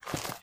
STEPS Dirt, Walk 30.wav